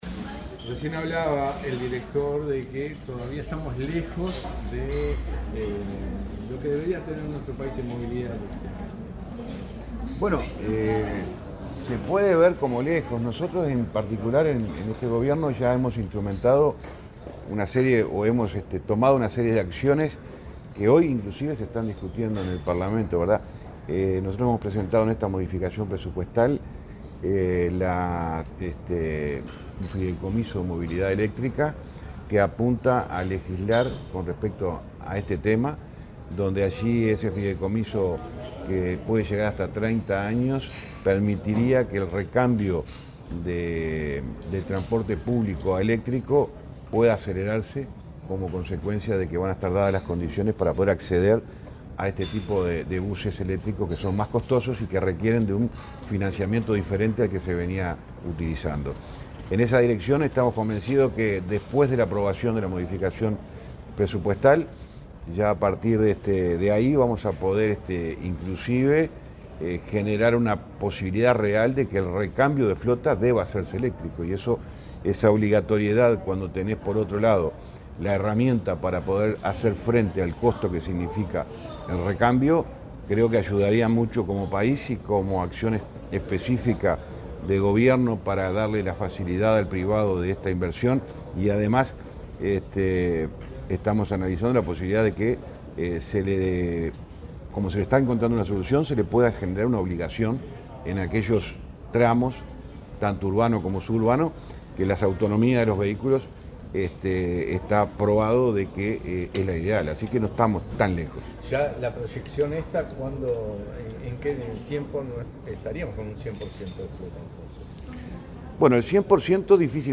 Declaraciones del ministro de Transporte, José Luis Falero
Este jueves 28 en Montevideo, el ministro de Transporte y Obras Públicas, José Luis Falero, dialogó con la prensa, antes de participar III Foro